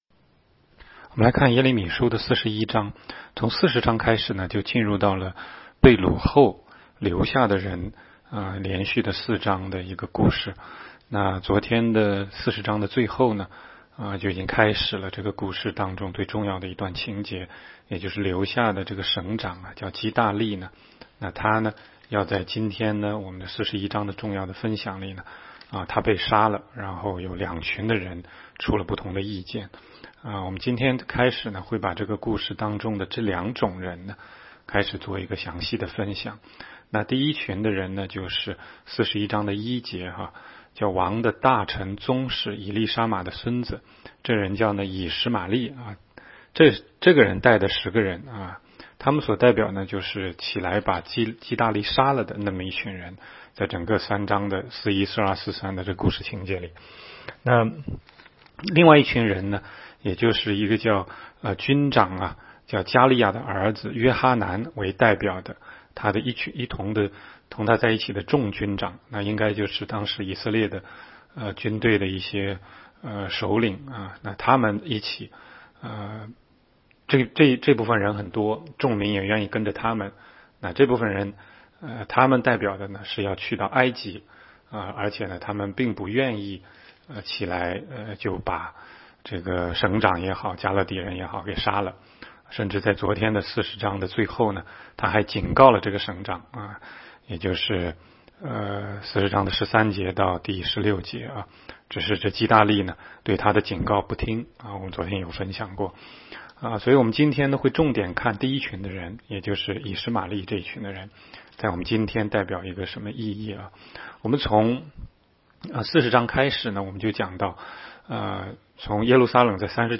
16街讲道录音 - 每日读经 -《耶利米书》41章